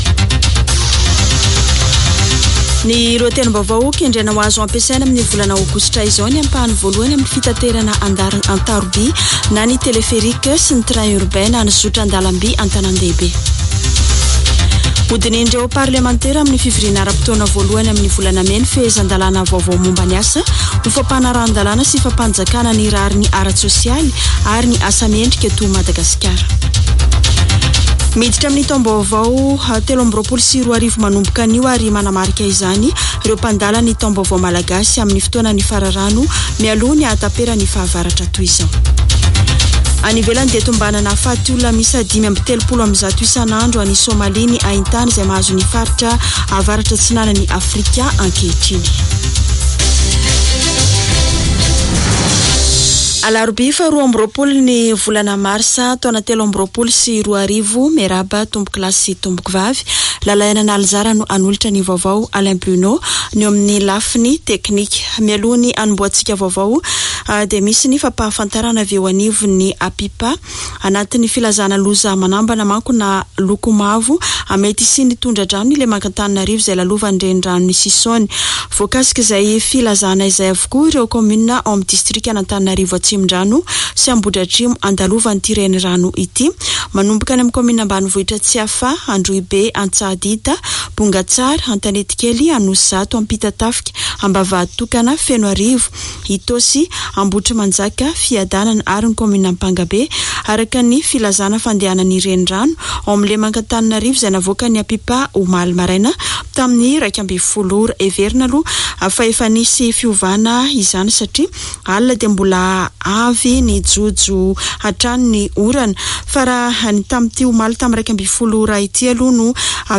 [Vaovao maraina] Alarobia 22 marsa 2023